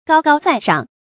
注音：ㄍㄠ ㄍㄠ ㄗㄞˋ ㄕㄤˋ
高高在上的讀法